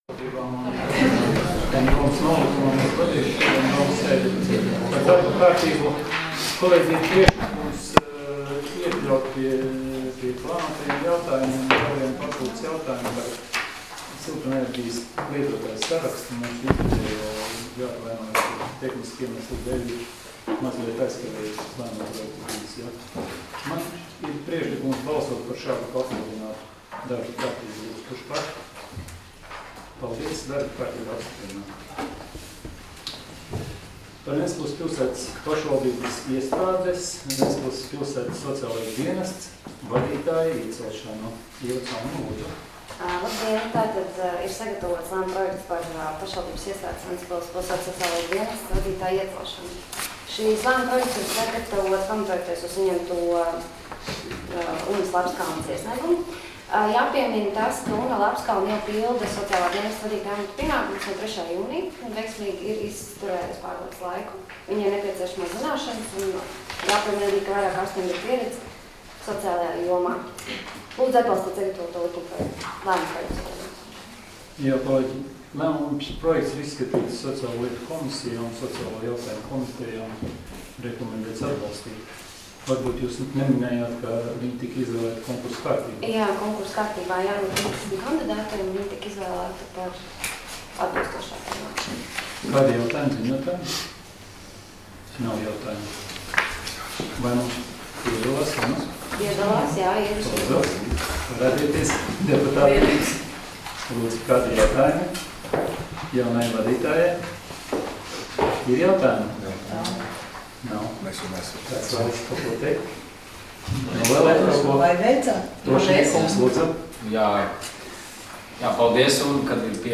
Domes sēdes 27.09.2019. audioieraksts